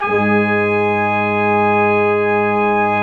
Index of /90_sSampleCDs/Roland LCDP06 Brass Sections/BRS_Quintet/BRS_Quintet long